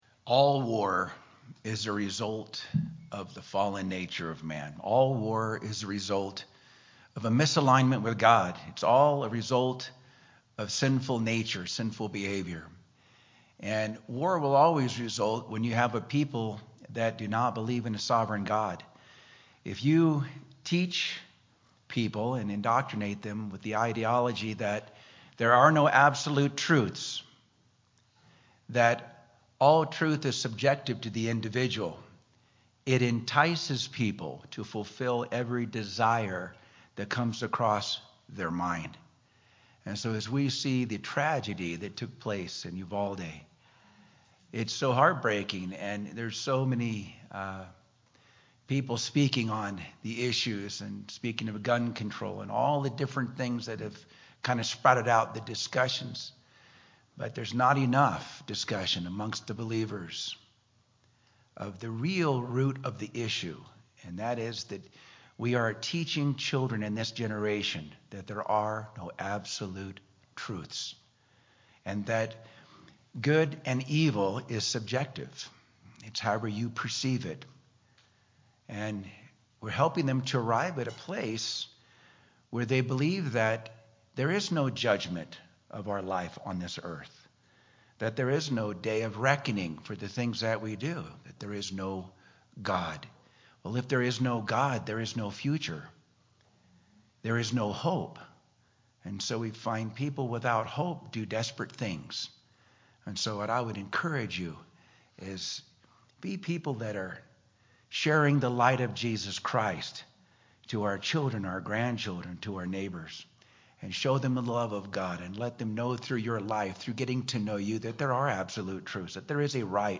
Memorial Day Service 2022